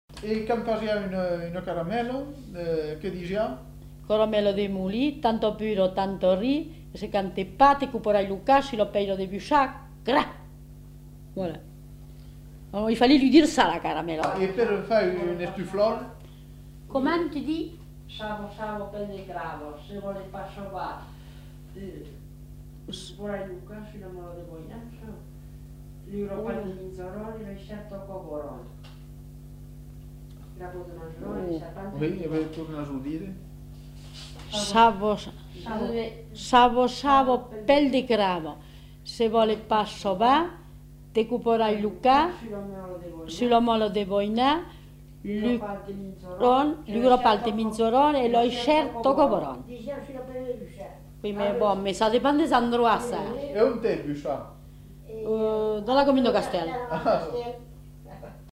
Aire culturelle : Périgord
Lieu : Castels
Type de voix : voix de femme
Production du son : récité
Classification : formulette